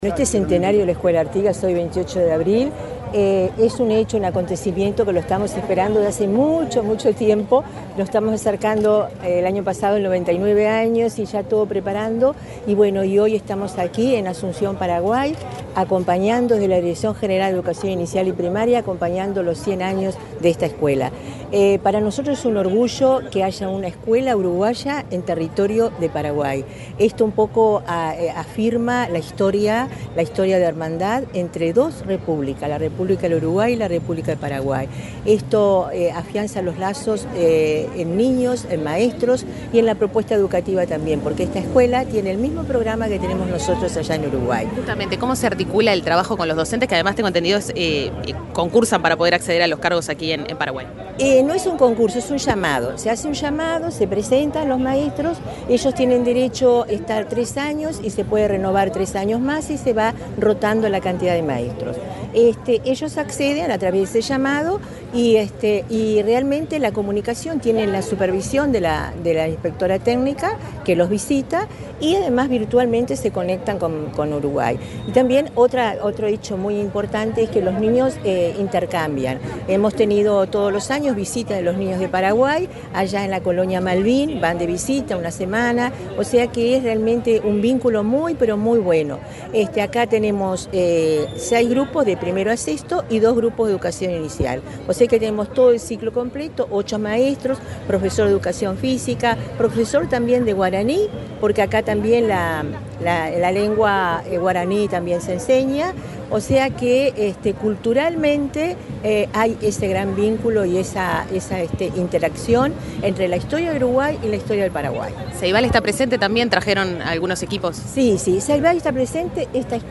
Entrevista a la directora general de Educación Inicial y Primaria
Declaraciones de Olga de las Heras a Comunicación Presidencial, en el marco de la celebración de los 100 años de la escuela uruguaya Artigas, en